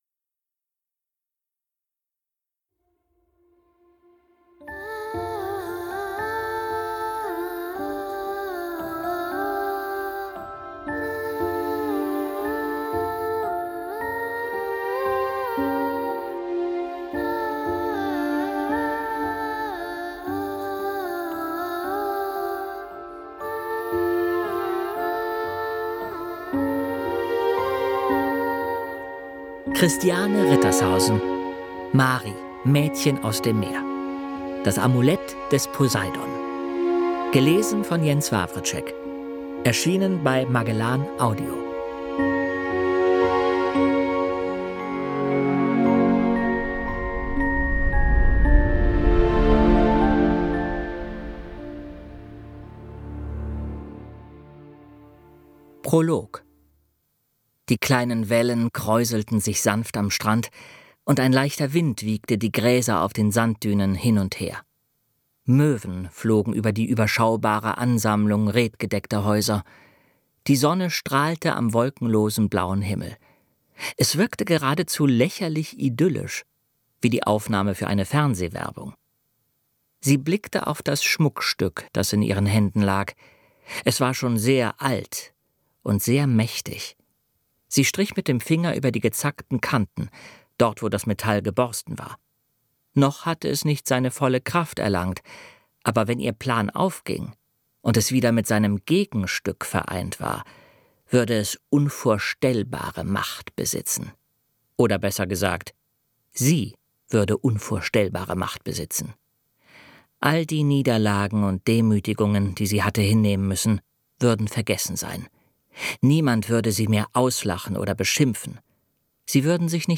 Jens Wawrczeck (Sprecher)